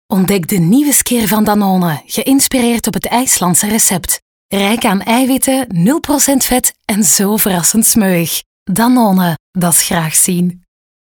Diep, Jong, Speels, Veelzijdig, Warm
Corporate
Her professionalism, experience and authentic tone of voice make her a reliable choice for clients looking for quality and impact.